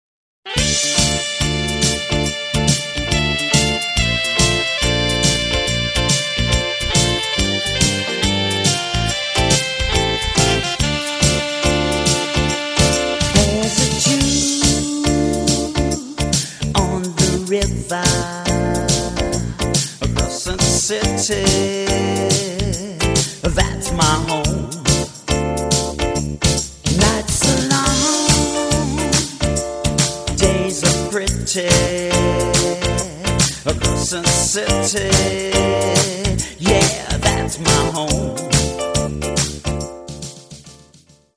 Real Audio-40Kbps mono